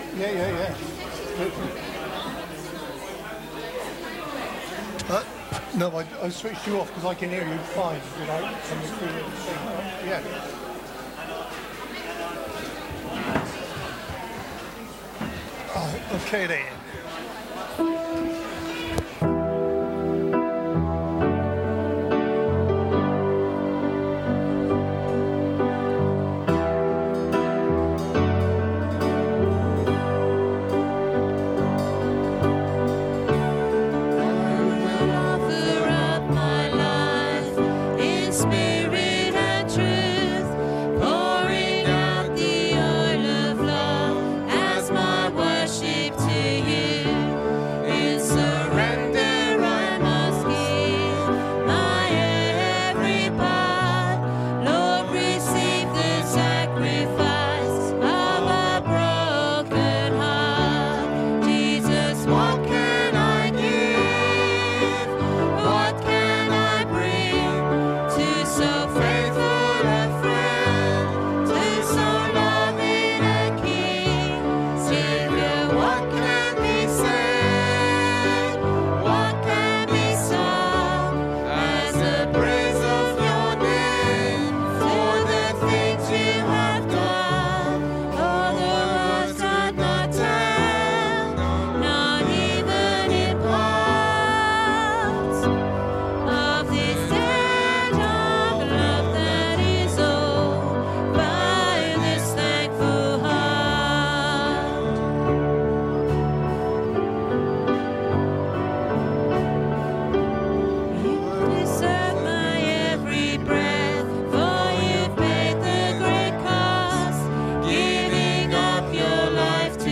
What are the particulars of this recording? Join us for a communion service and the latest in our sermon series, Under Construction.